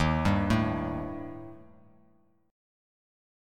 D#Mb5 chord